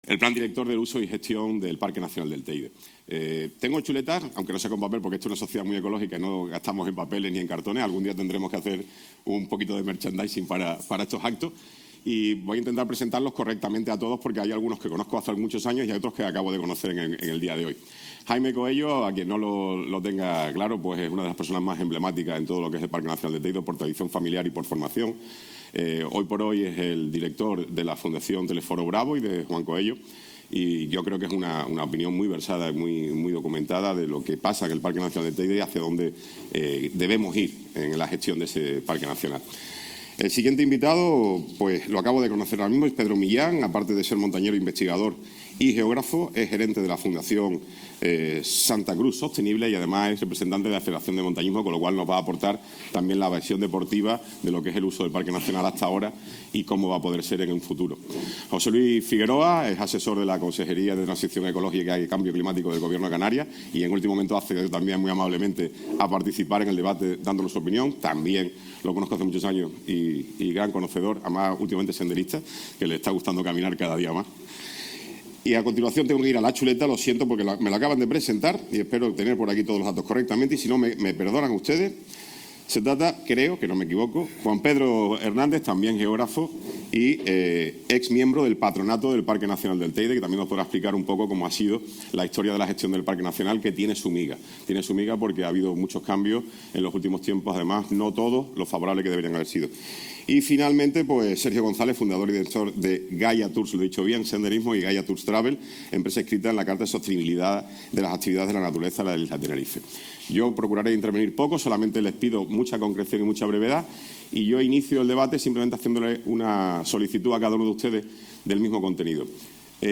Debate informativo sobre el Plan Rector de uso y gestión del Parque Nacional del Teide. 9 de junio – Gente Radio